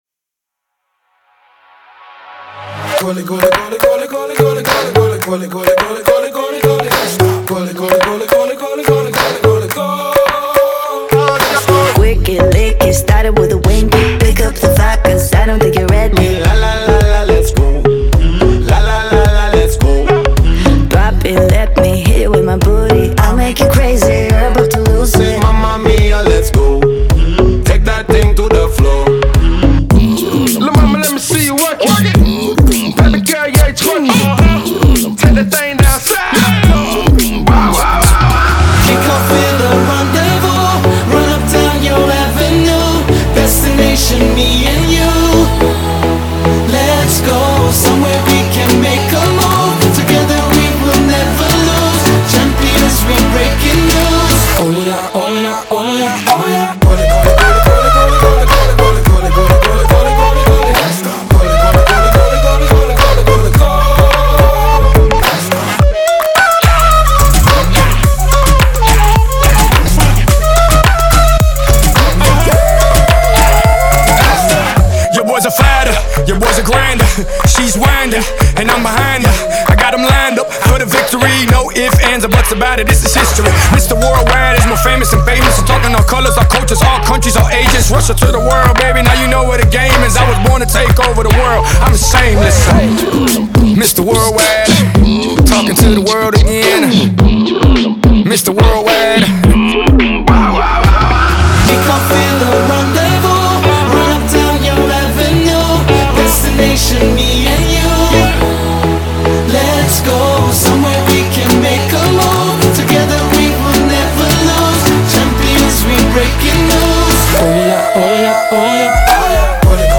это зажигательная композиция в жанре поп и хип-хоп